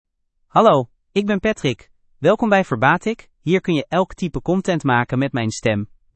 MaleDutch (Netherlands)
Patrick — Male Dutch AI voice
Patrick is a male AI voice for Dutch (Netherlands).
Voice sample
Listen to Patrick's male Dutch voice.
Patrick delivers clear pronunciation with authentic Netherlands Dutch intonation, making your content sound professionally produced.